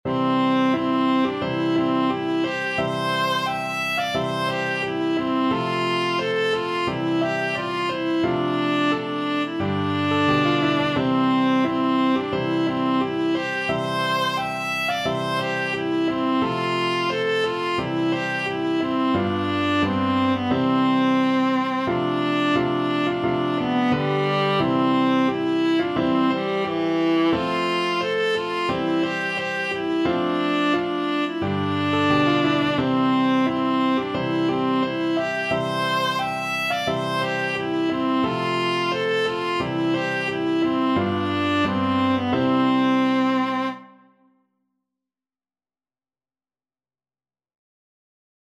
Viola
C major (Sounding Pitch) (View more C major Music for Viola )
4/4 (View more 4/4 Music)
Traditional (View more Traditional Viola Music)
Scottish